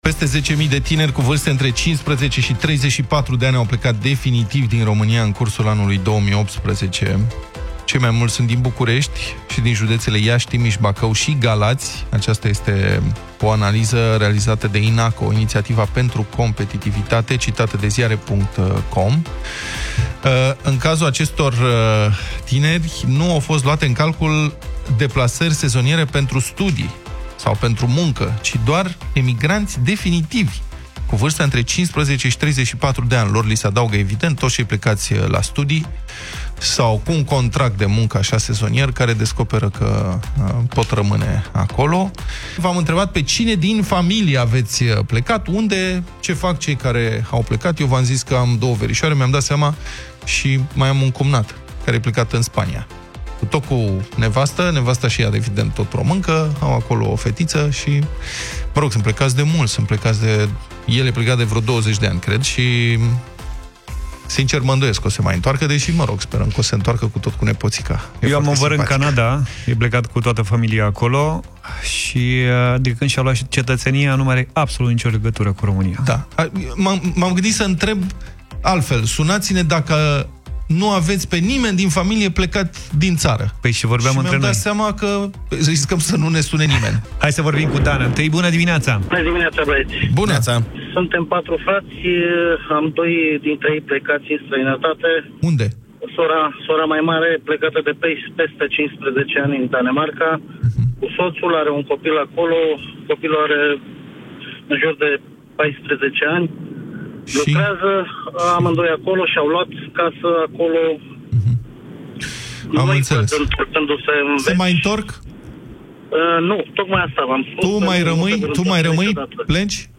Dezbaterea dimineții: Pe cine din familie aveți plecat din țară?
i-au întrebat pe ascultătorii Deșteptarea pe cine au ei din familie plecat în străinătate.